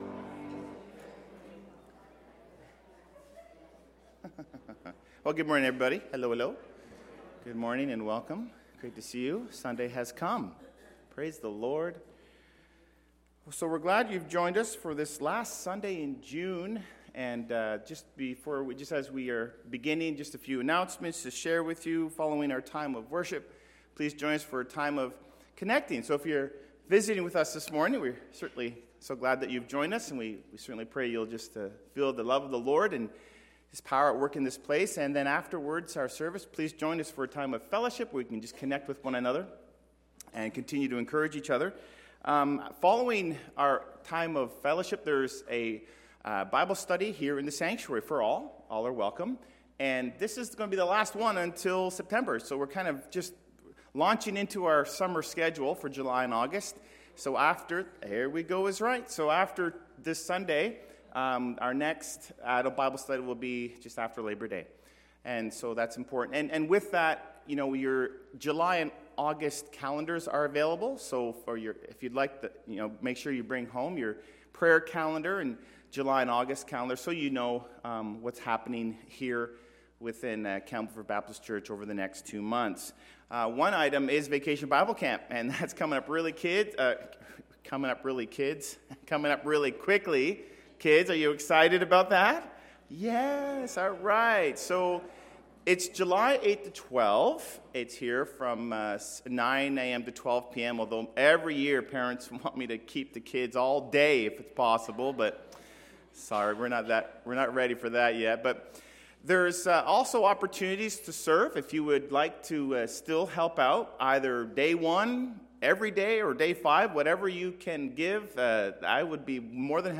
Video Sermons - Campbellford Baptist Church Inc.